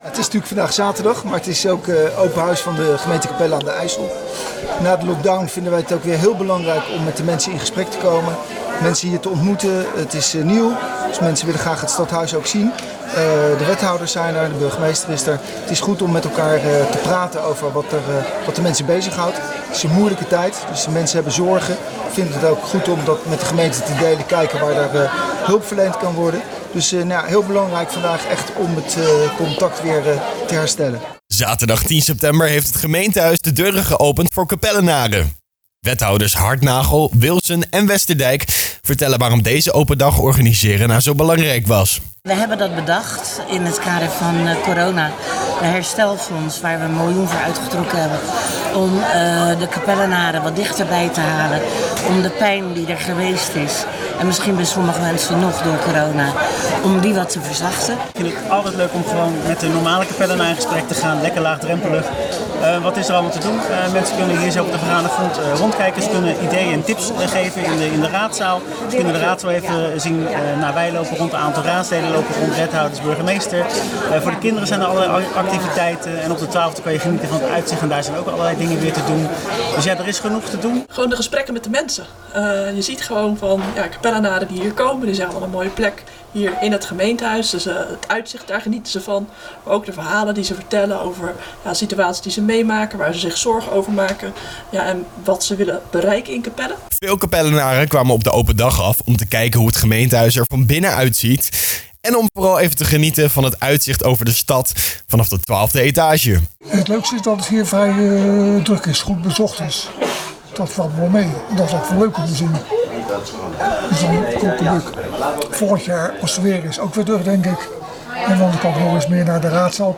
De gemeente Capelle aan den IJssel hield zaterdag 10 september een Open Dag in het gemeentehuis.